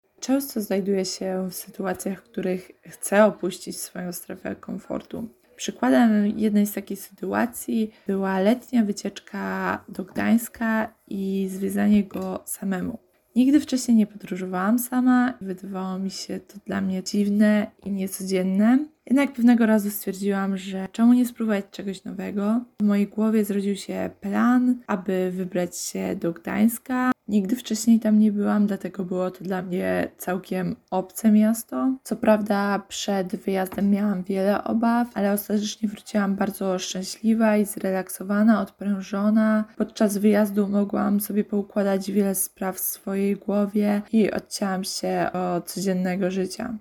Zapytaliśmy studentki, czy były kiedyś w sytuacji, w której musiały opuścić swoją strefę komfortu i jak poradziły sobie z dostosowaniem do nowej sytuacji: